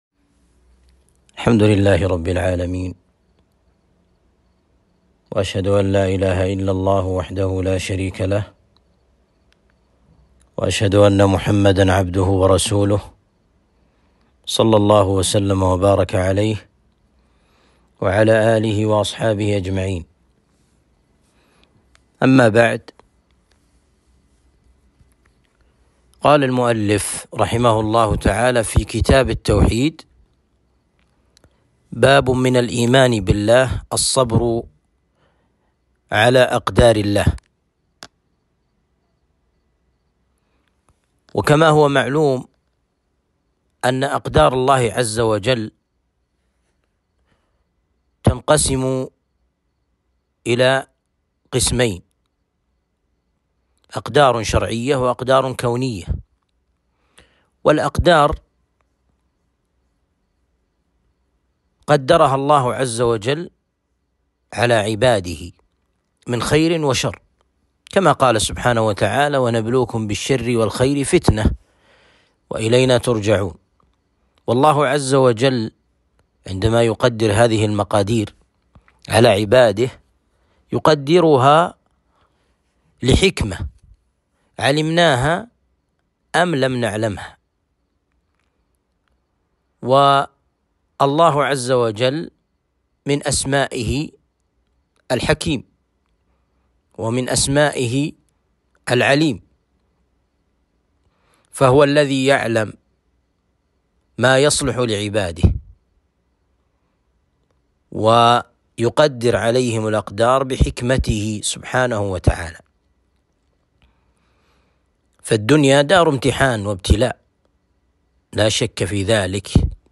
درس شرح كتاب التوحيد (35)